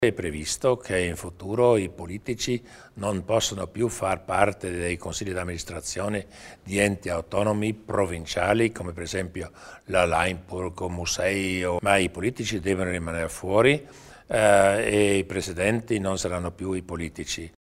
Il Presidente Durnwalder spiega le nuove regole in tema di trasparenza